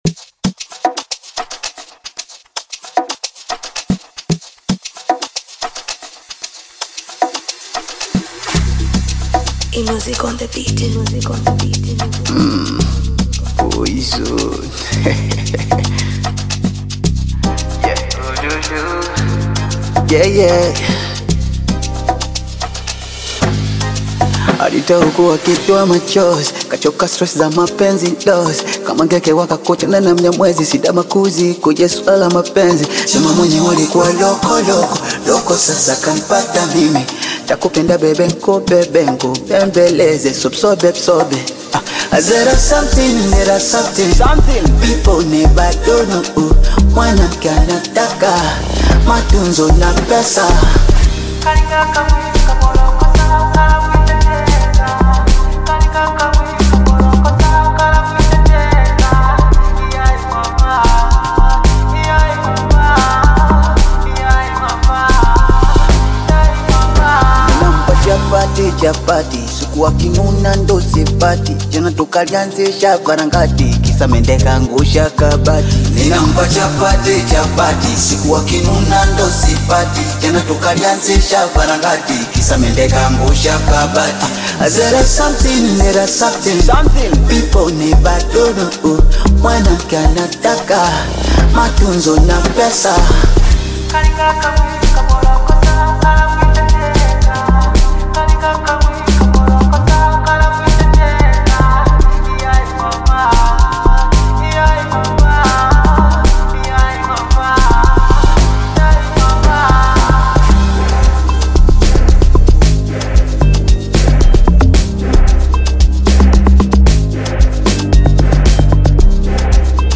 Bongo Amapiano
Bongo Flava